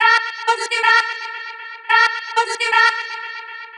• chopped vocals ping pong house delayed (9).wav
chopped_vocals_ping_pong_house_delayed_(9)_pHO.wav